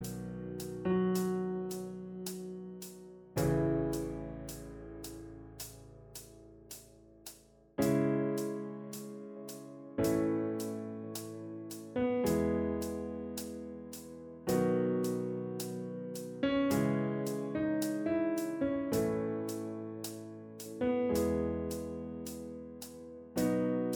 Minus All Guitars Pop (2010s) 4:16 Buy £1.50